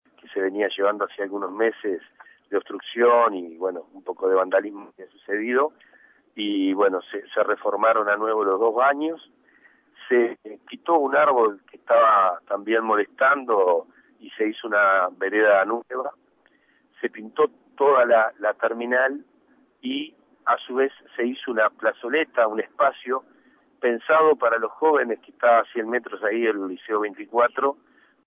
En diálogo con El Espectador, el alcalde del municipio A, Gabriel Otero, informó que la obra fue planificada por el consejo vecinal y la comisión de obras del gobierno departamental con presupuesto de la Intendencia.